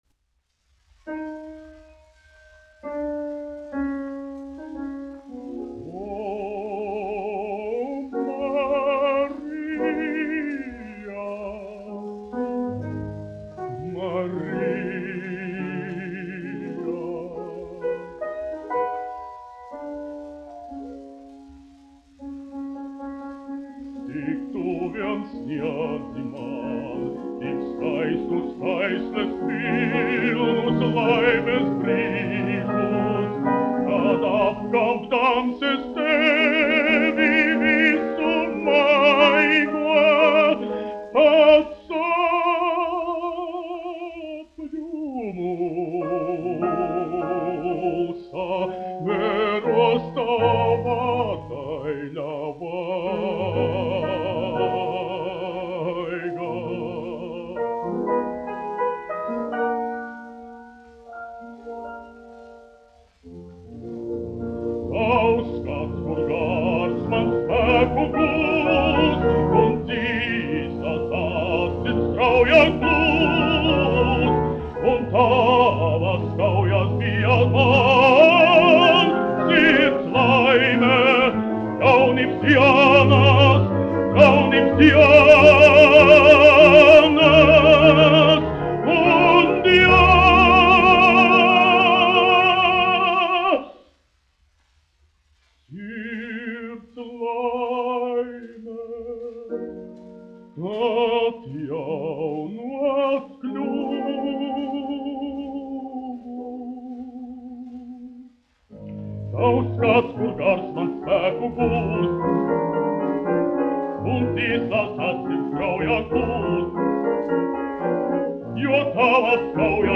Kaktiņš, Ādolfs, 1885-1965, dziedātājs
1 skpl. : analogs, 78 apgr/min, mono ; 25 cm
Operas--Fragmenti, aranžēti
Skaņuplate
Latvijas vēsturiskie šellaka skaņuplašu ieraksti (Kolekcija)